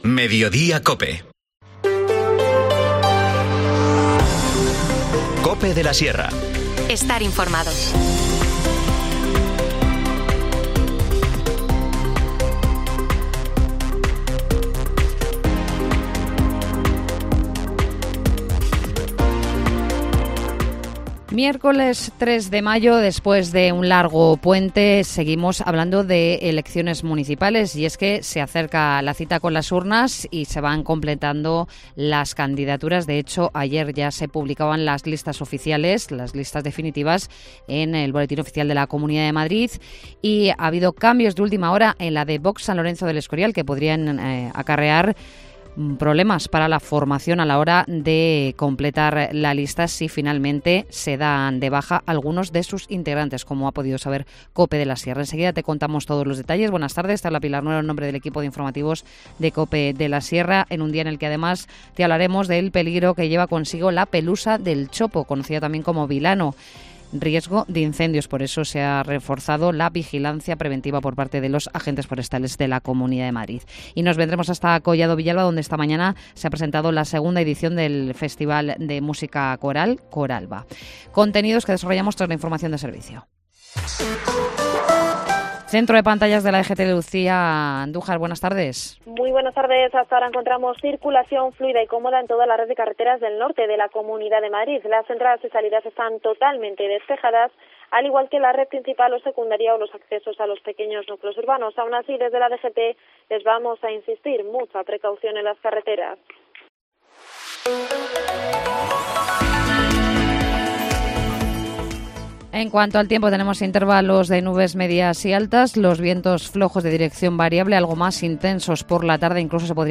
Informativo Mediodía 3 mayo